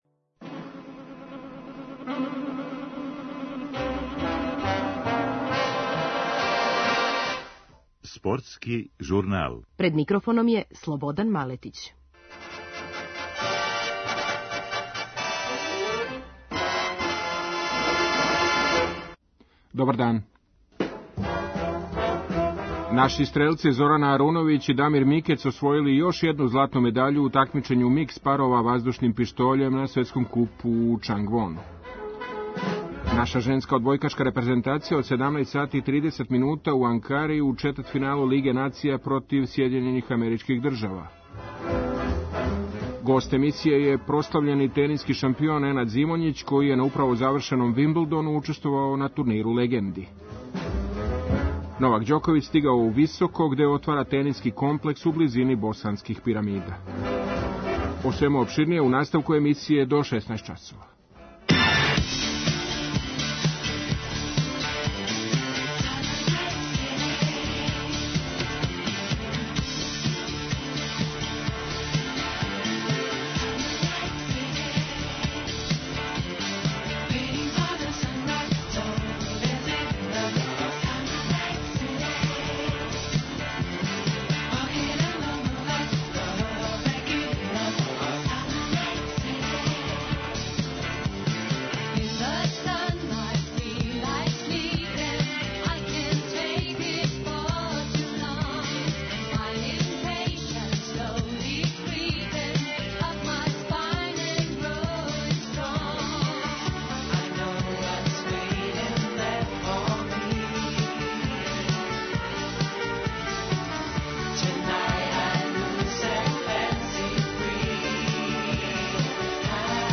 Гост емисије је прослављени тениски шампион Ненад Зимоњић, који је на управо завршеном Вимблдину учествовао на турниру легенди у част стогодишњице централног терена и победио у микс дублу са Марион Бартоли.